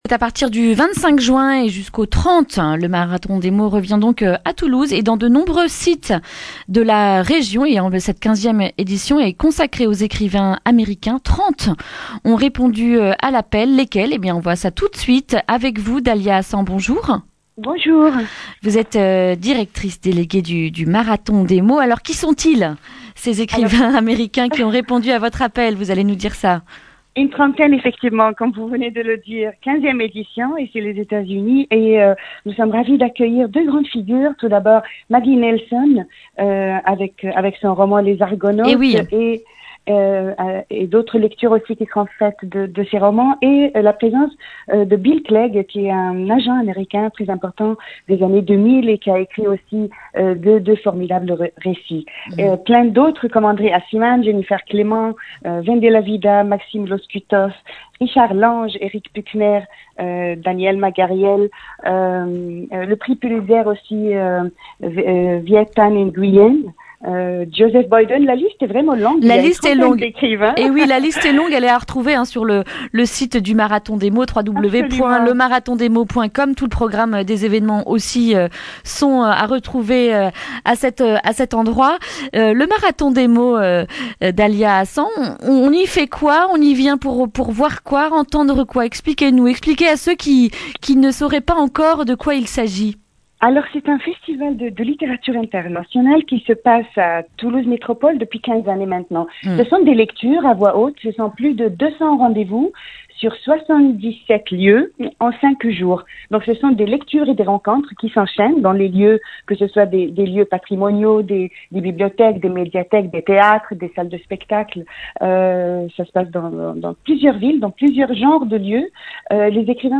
jeudi 20 juin 2019 Le grand entretien Durée 11 min